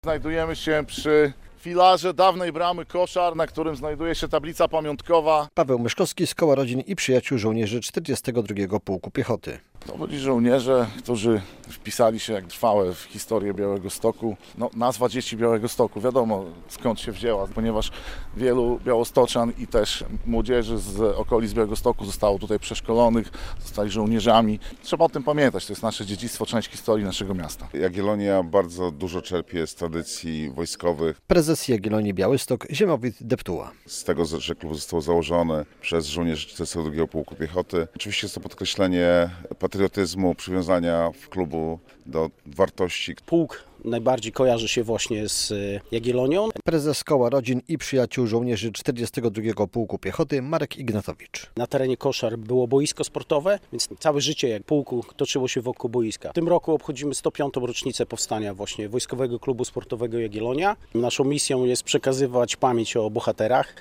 Święto 42. Pułku Piechoty - uroczystości przed pomnikiem i dawną bramą koszar